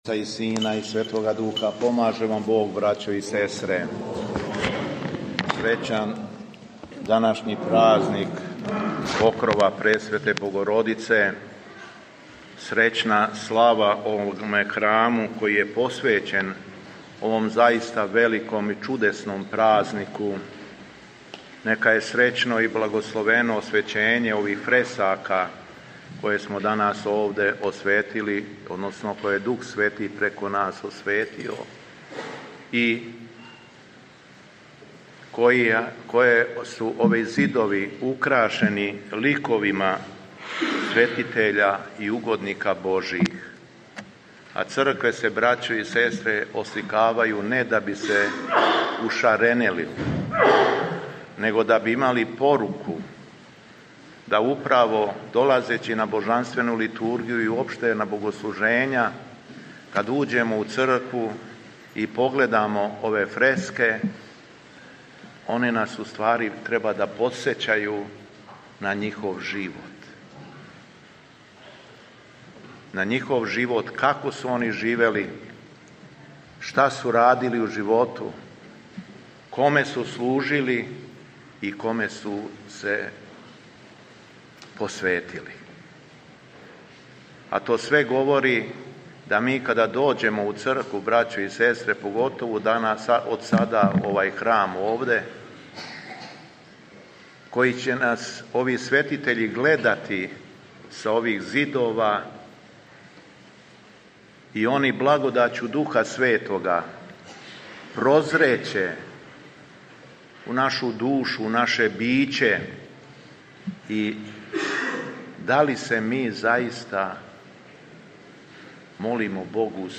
Беседа Његовог Високопреосвештенства Митрополита шумадијског г. Јована
У уторак 14. октобра 2025. године, када Црква прославља празник Покрова Пресвете Богородице Његово Високопреосвештенство Митрополит шумадијски служио је архијерејску Литургију у Барошевцу надомак Лазаревца поводом храмовне славе.